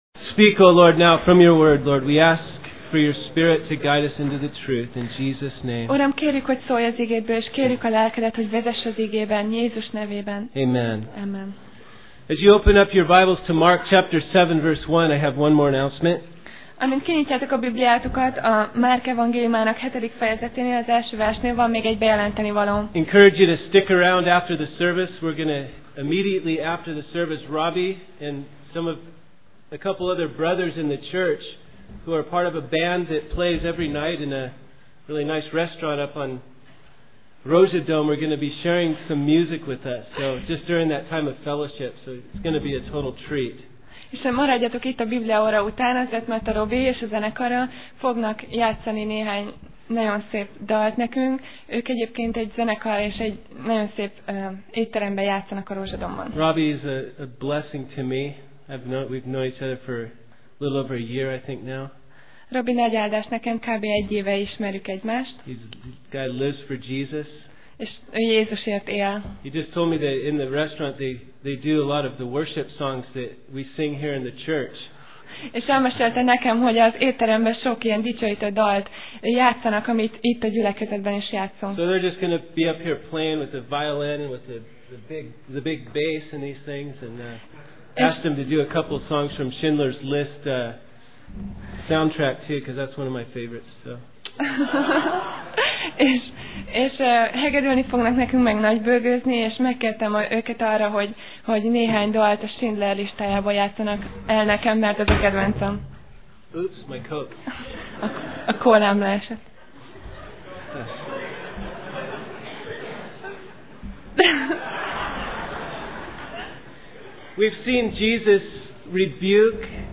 Passage: Márk (Mark) 7:1-23 Alkalom: Vasárnap Reggel